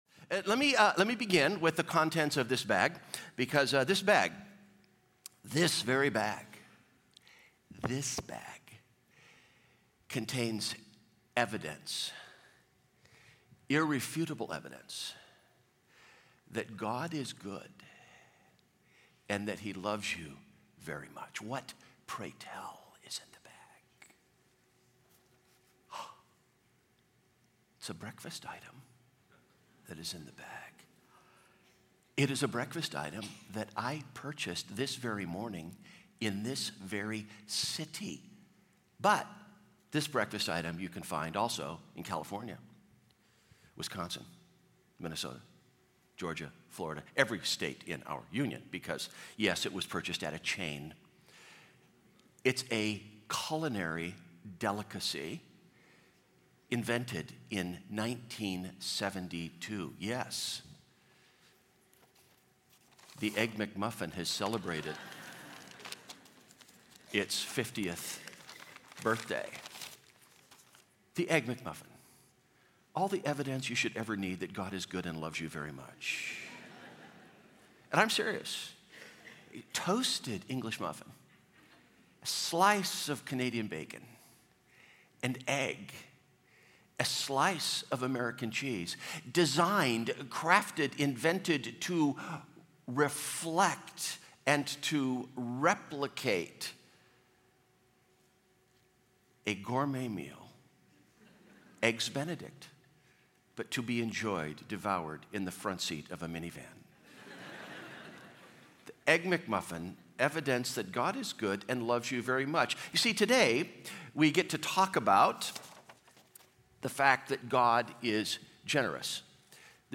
Sermon Discussion